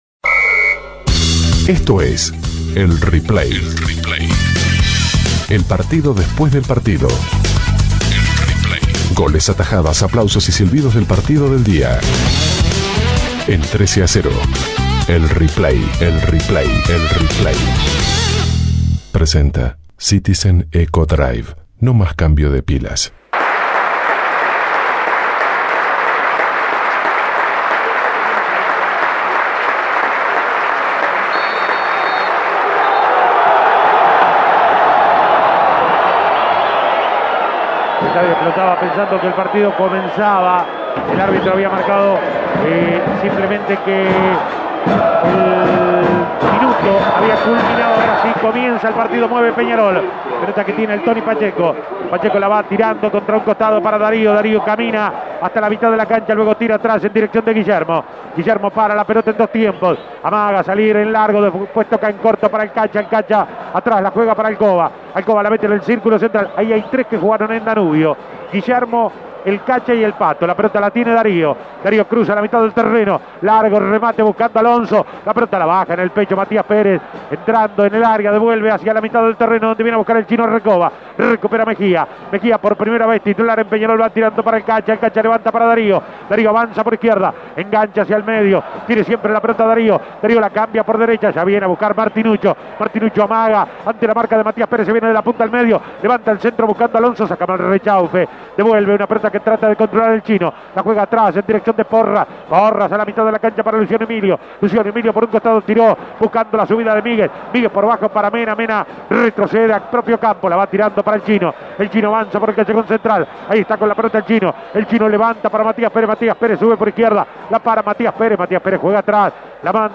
Goles y comentarios Escuche el Replay de Peñarol - Danubio Imprimir A- A A+ Tras el duro revés sufrido días atrás por la Copa Sudamericana, Peñarol recuperó la senda de la victoria y derrotó a Danubio por 1 a 0.